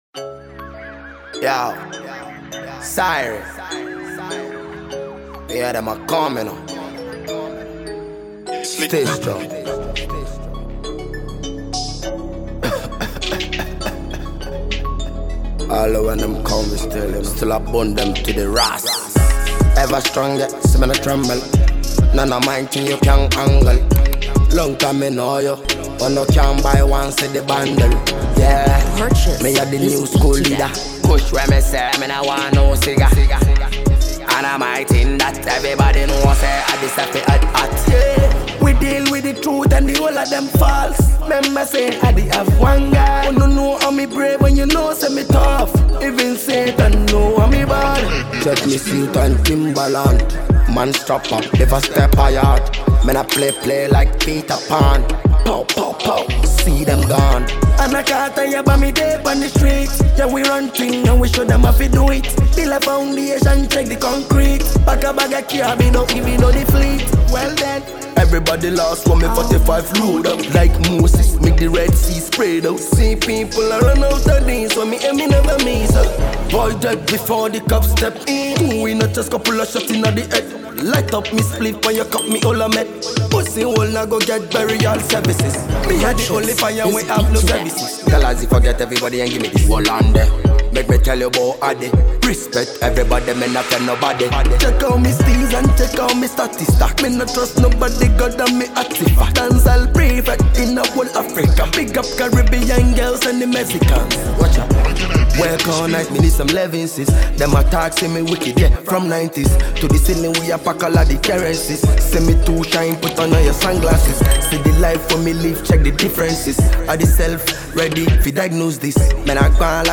hot freestyle